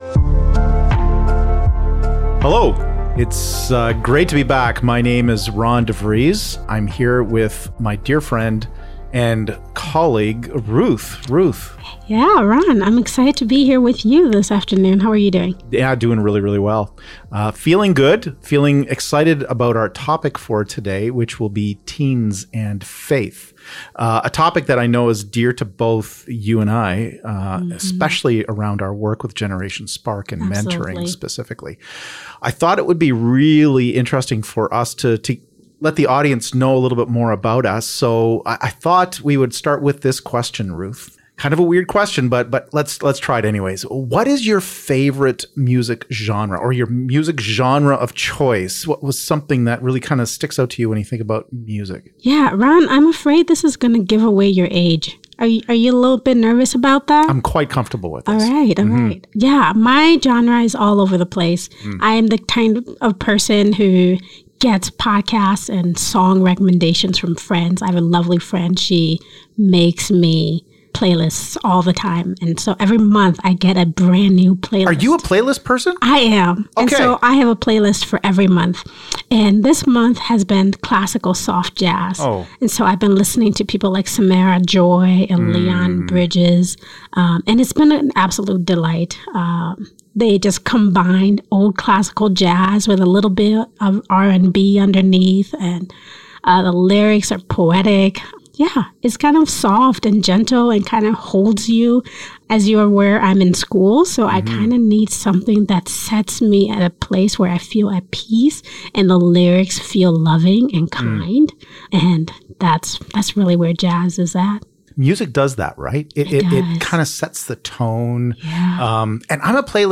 Connecting life, theory, practice and ministry with an intergenerational conversation.&nbsp